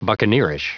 Prononciation du mot buccaneerish en anglais (fichier audio)
Prononciation du mot : buccaneerish